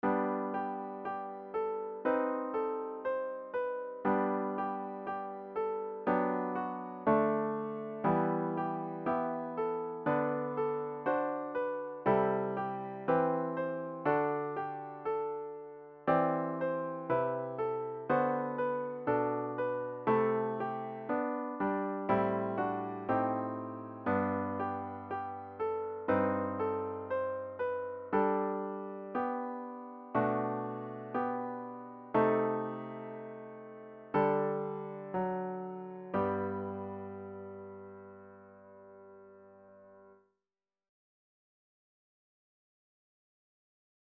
The hymn should be performed at a contented♩= ca. 60.